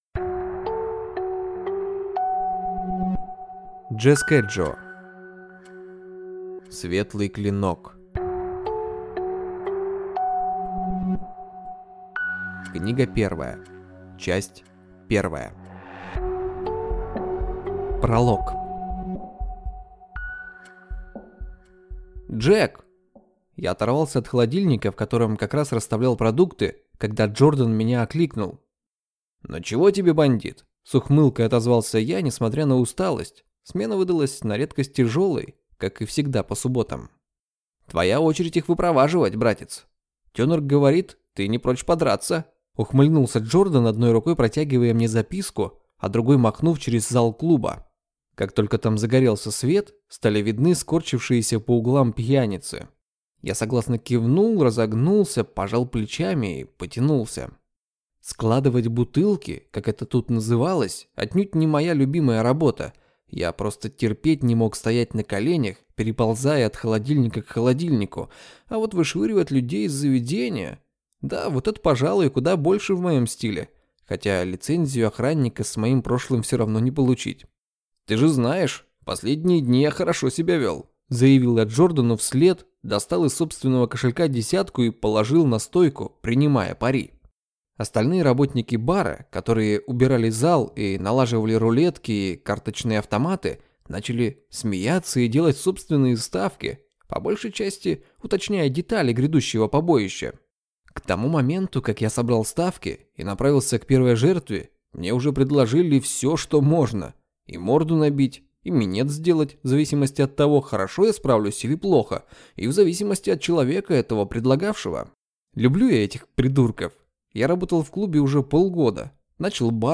Аудиокнига Клинок света. Книга первая | Библиотека аудиокниг